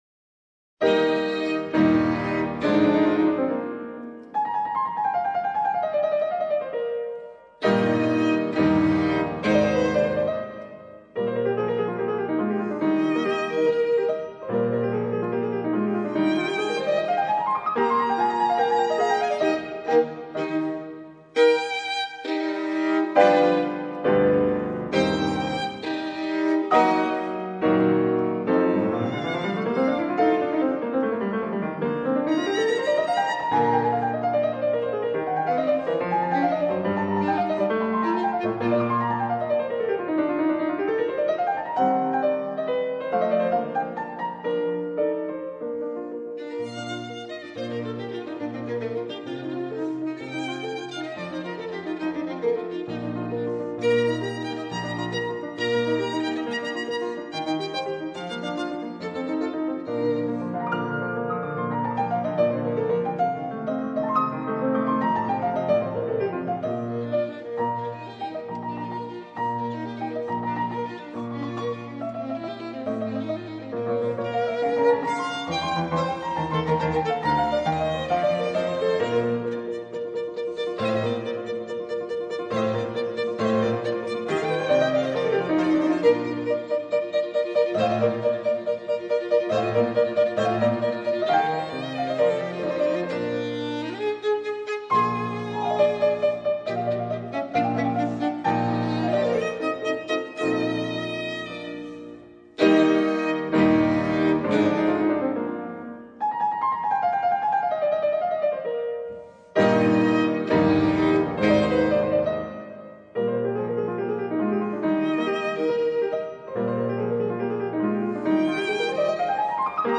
音乐类型：古典音乐
E大调奏鸣曲+B大调奏鸣曲+G大调奏鸣曲 聆听每个都是真善美化身的音符 鉴赏每一段皆是艺术结晶的节奏。